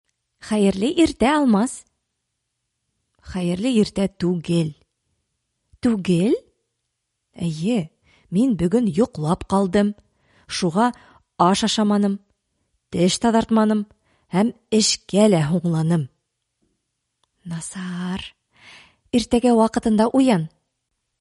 Диалог